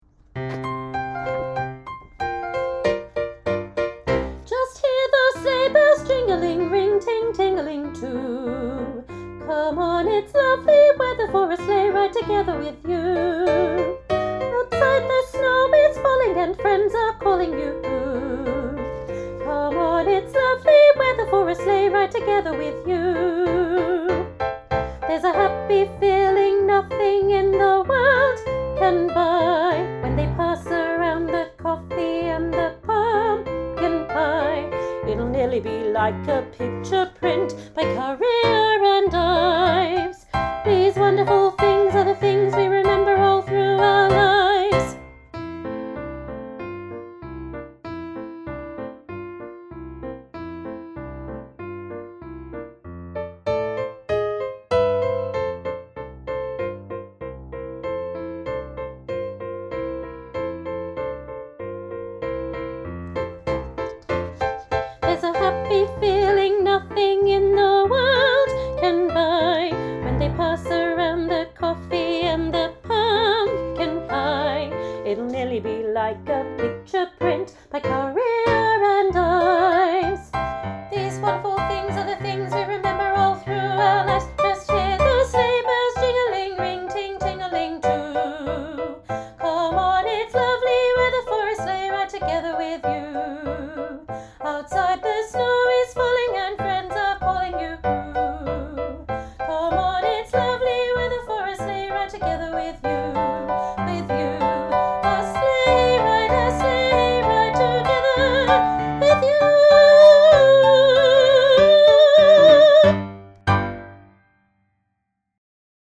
Elementary Choir, Sleigh Ride – Combined Piece, Middle Part And Stave
Elementary-Choir-Sleigh-Ride-Combined-Piece-middle-part-and-stave.mp3